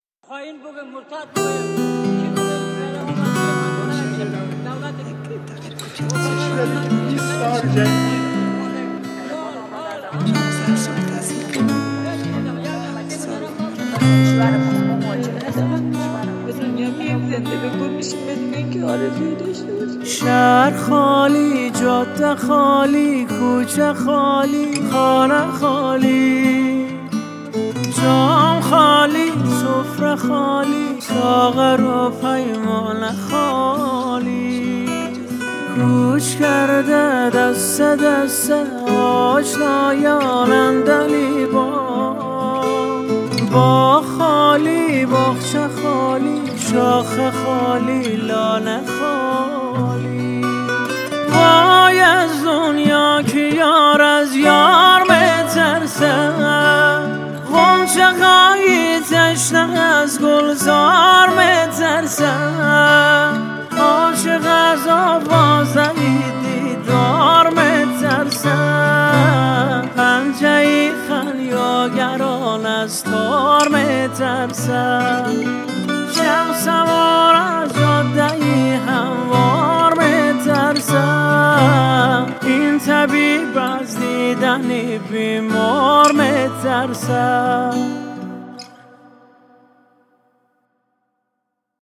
• دسته آهنگ پاپ